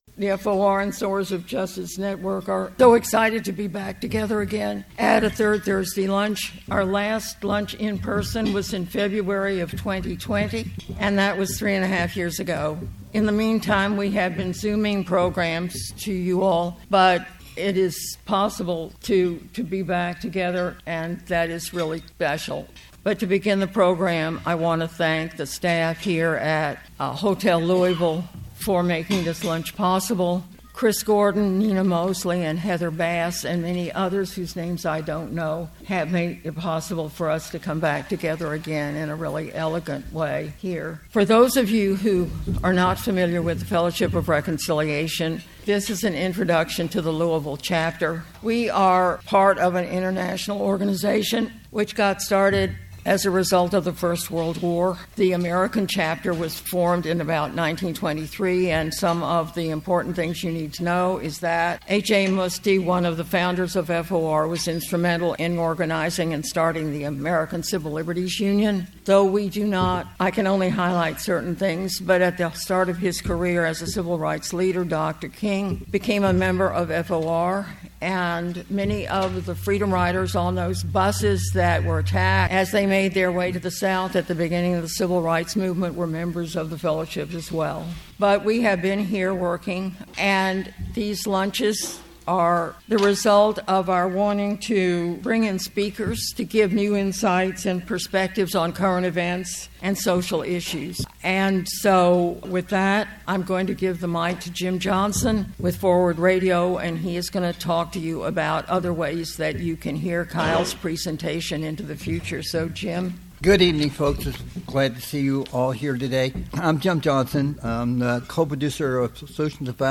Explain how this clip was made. Our Monthly Third Thursday Lunches bring in speakers to give new insights and perspectives on current events and social issues.